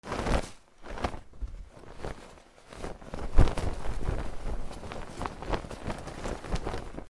布のこすれる音
/ J｜フォーリー(布ずれ・動作) / J-05 ｜布ずれ